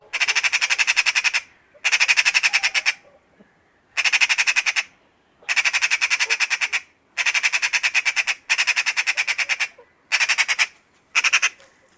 Ensin sinun tulee tunnistaa ääninäytteissä A-H esiintyvät luonnon omat laulajat ja sitten laskea niiden suomenkielisen lajinimen kirjainten määrä.